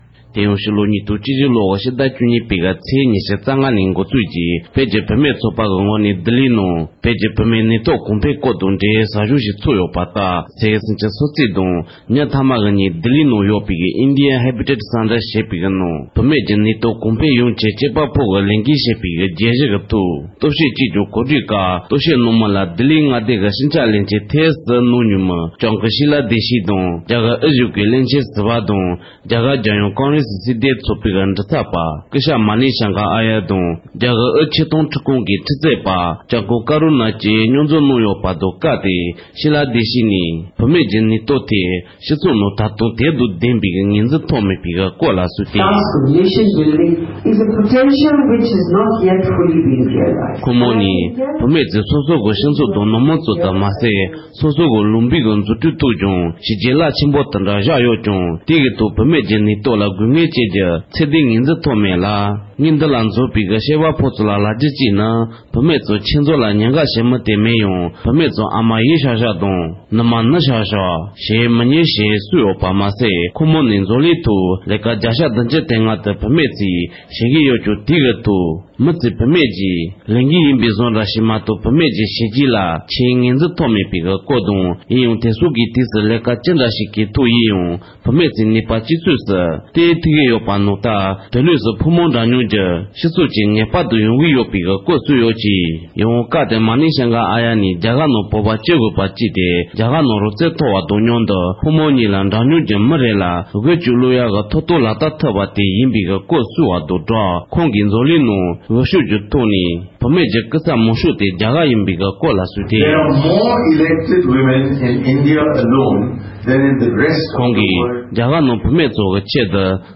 གནས་འདྲི་གླེང་མོལ